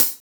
SLOP CHH 2.wav